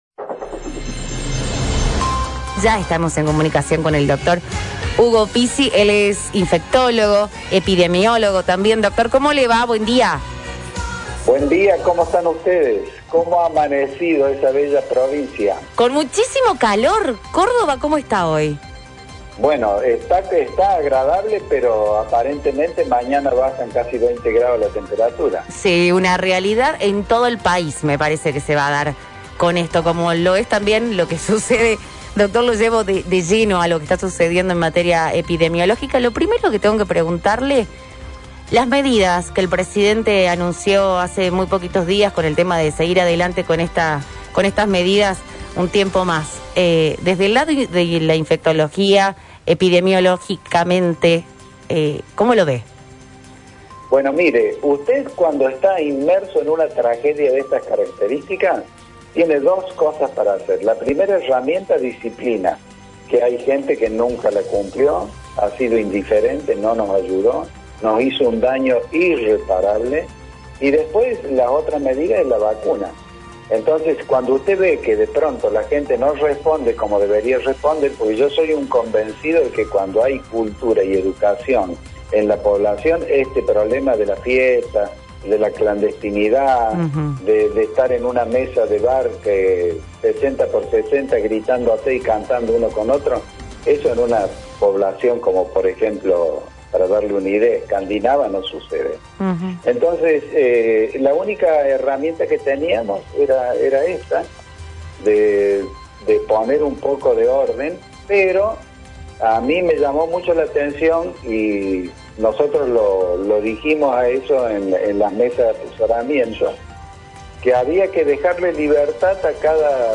En la entrevista realizada por La Mañana de City, las temáticas rondaron los puntos más hablados en la sociedad en su conjunto, pero también; de una manera didáctica y simple, como ya nos tiene acostumbrado este profesional de la salud; se apuntó a ese grupo que todavía no toma conciencia.